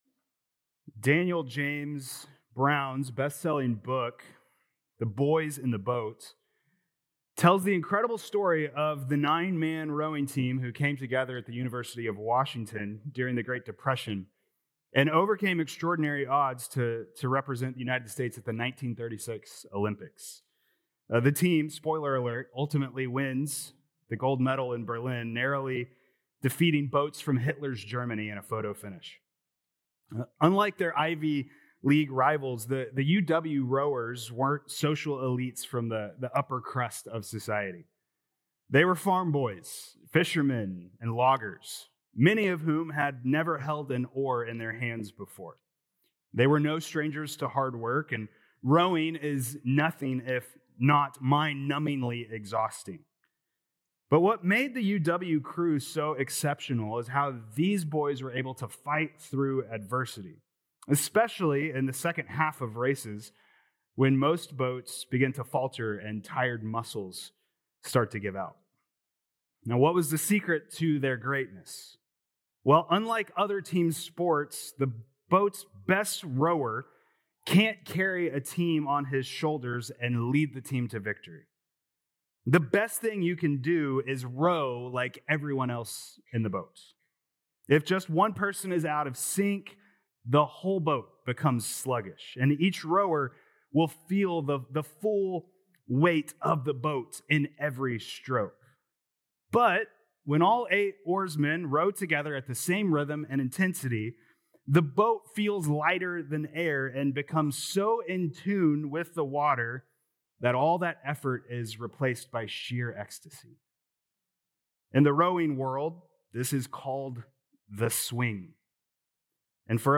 July 6th Sermon | Philippians 1:27-2:4